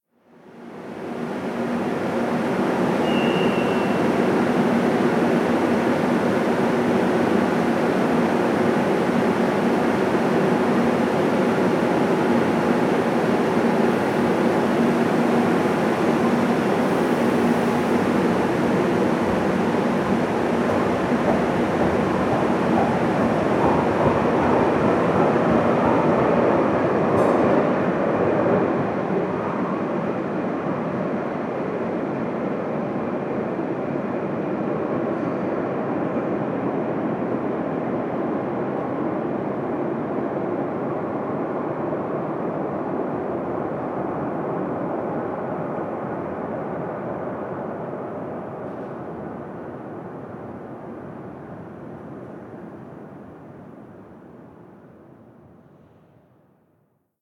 Salida del Metro
subterráneo
Sonidos: Transportes
Sonidos: Ciudad